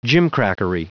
Prononciation du mot gimcrackery en anglais (fichier audio)
gimcrackery.wav